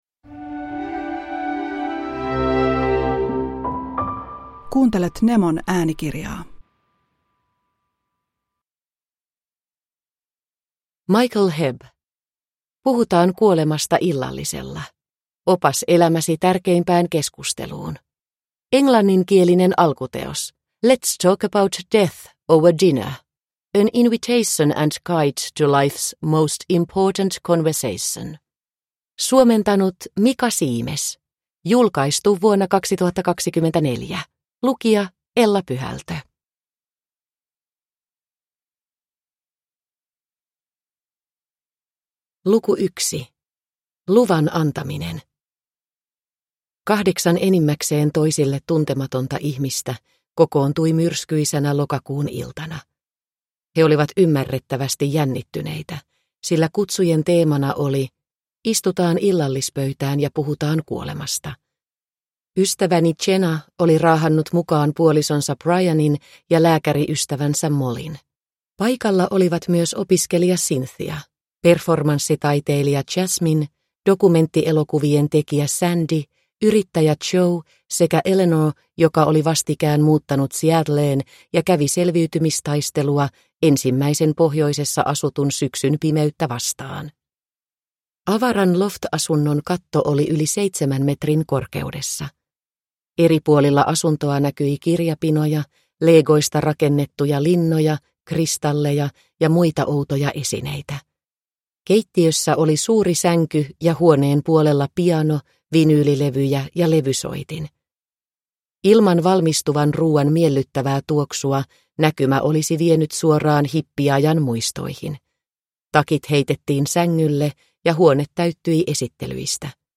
Puhutaan kuolemasta illallisella – Ljudbok